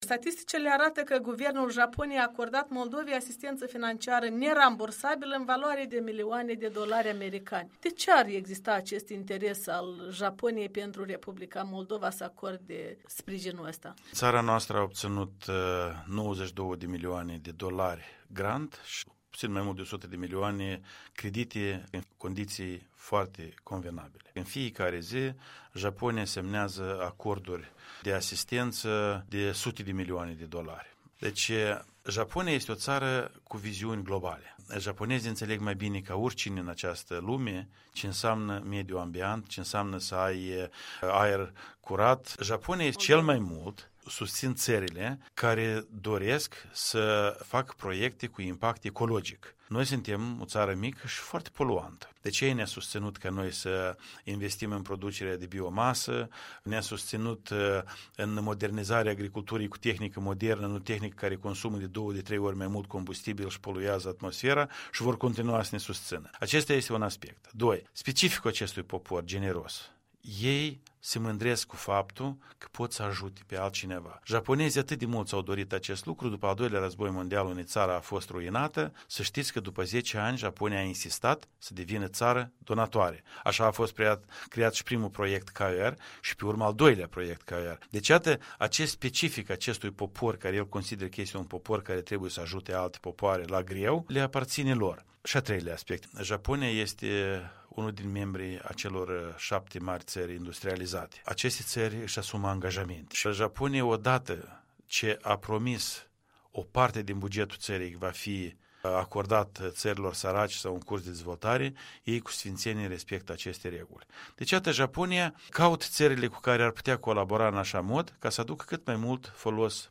Interviu cu noul ambasador al Moldovei la Tokyo.